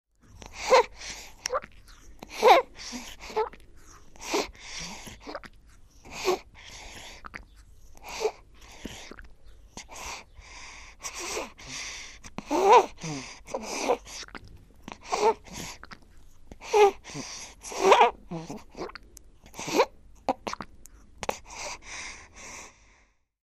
Newborn Baby Eat, Twist